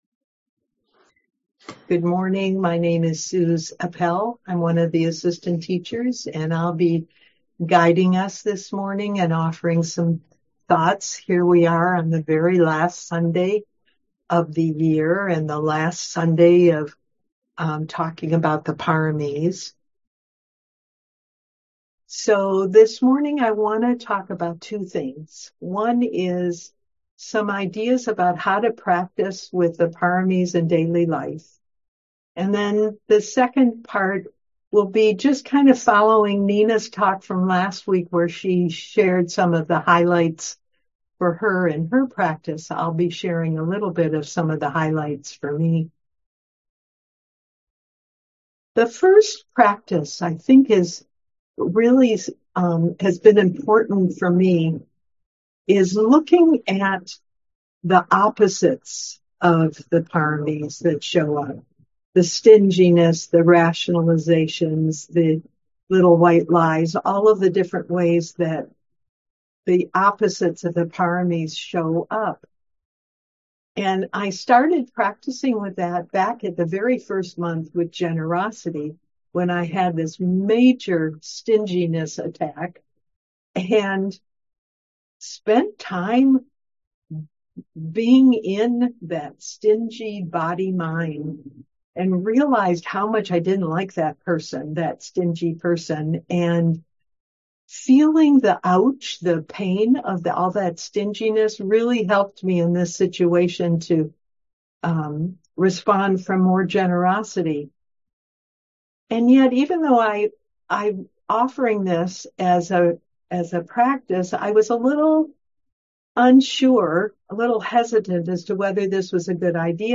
Audio recordings of Buddhist teachings and discussions with local and visiting teachers of the Dhamma.